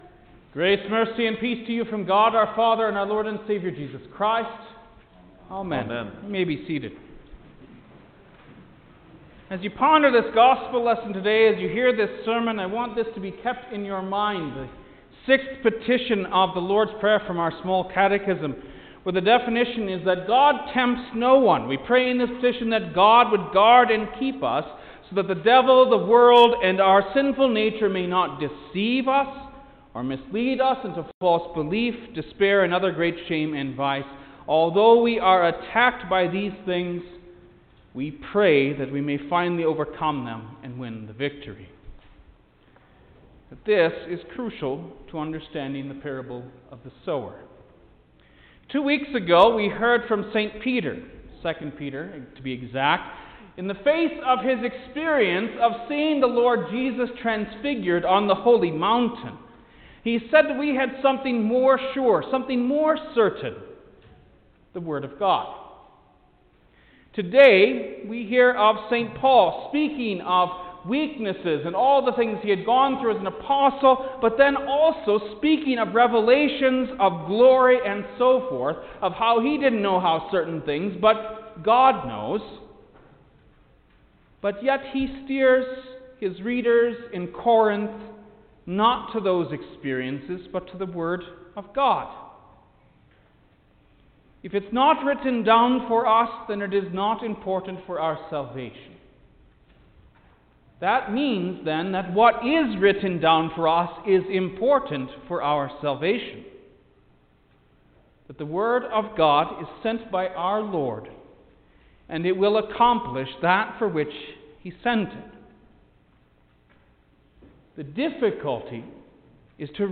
February-7_2021_Sexagesima_Sermon_Stereo.mp3